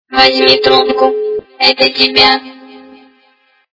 При прослушивании Голос инопланетянина - Возьми трубку, это тебя! качество понижено и присутствуют гудки.
Звук Голос инопланетянина - Возьми трубку, это тебя!